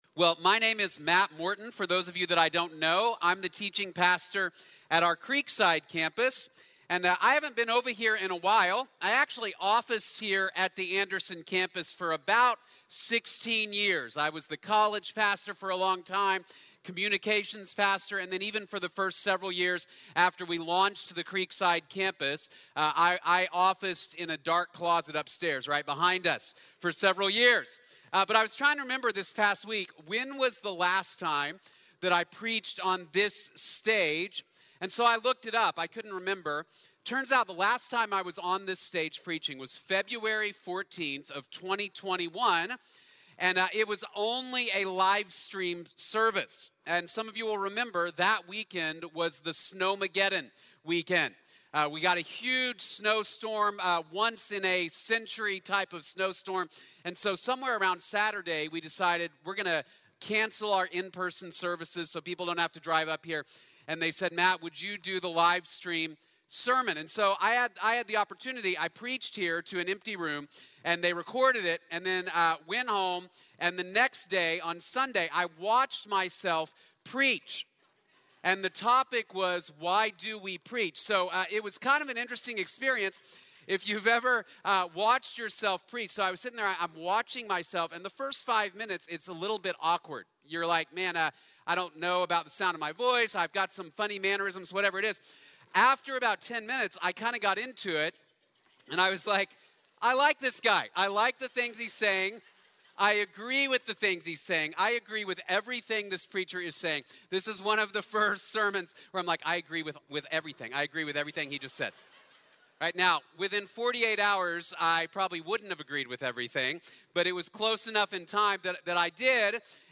The Bible | Sermon | Grace Bible Church